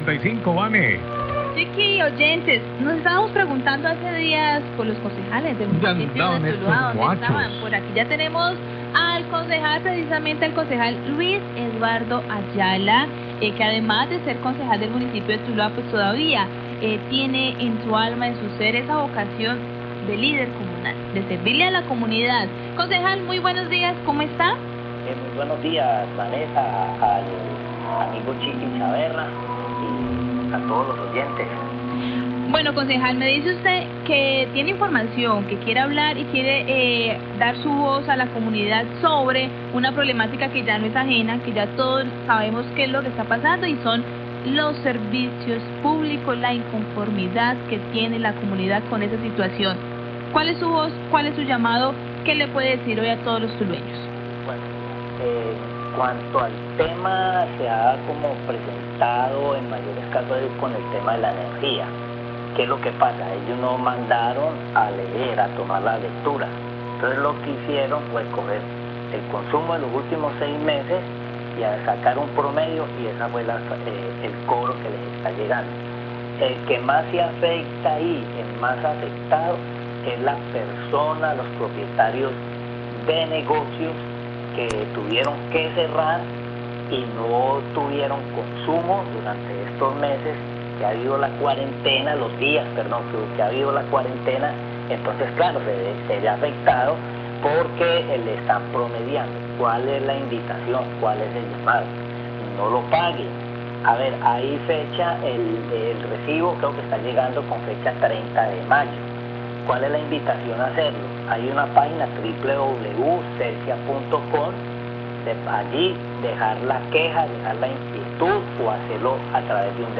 Concejal habla de las inconformidades de la comunidad por incremento en el valor de los servicios públicos, los robles 755AM
Radio
El concejal Ayala de Tuluá habla de la inconformidad que se viene presentando en la comunidad por el incremento injustificado que se está presentando en las facturas del servicio de energía, afirma que los recibos no deben pagarse hasta que la empresa explique lo que pasó.